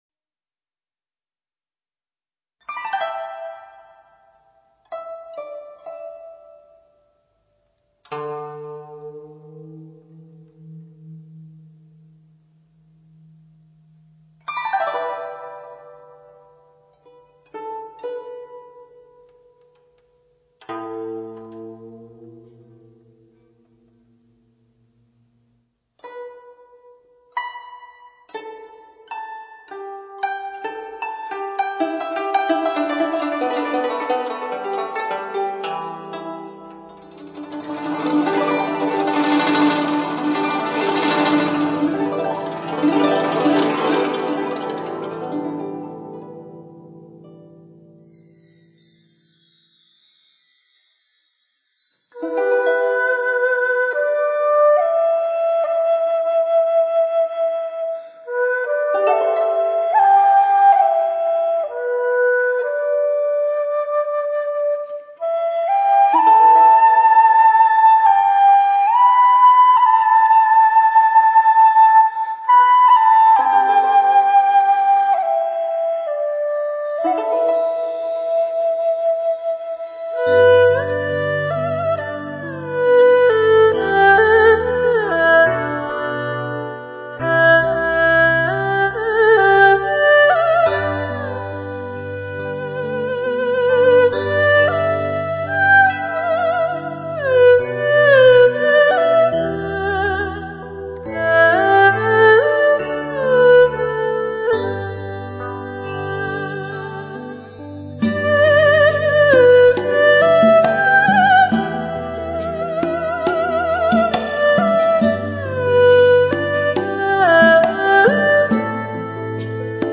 二胡演奏
采用最新美国DTS-ES6.1顶级编码器创造超乎想象完美环绕声震撼体验。